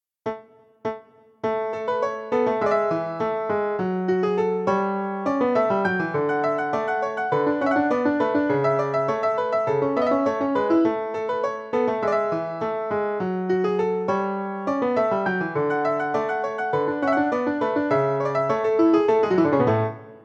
間奏のノーマルスピード（テンポ102）戻しヴァージョン（音が出ます）